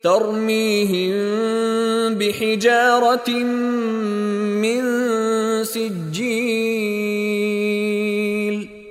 Contoh Bacaan dari Sheikh Mishary Rashid Al-Afasy
Bunyi Huruf Mim DISEMBUNYIKAN / DISAMARKAN dengan merengangkan bibir ke dalam sebutan huruf Ba beserta dengung 2 harakat.